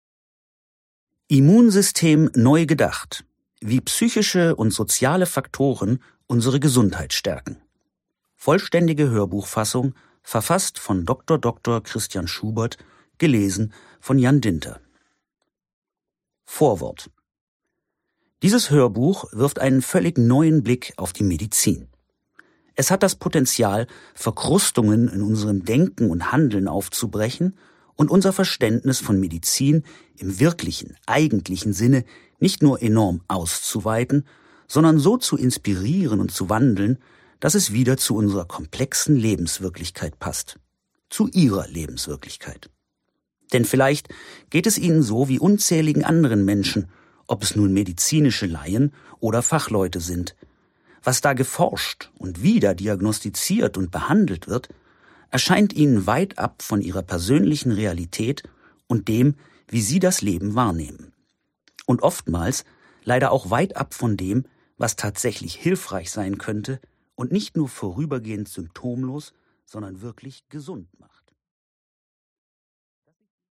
2025 | Ungekürzte Lesung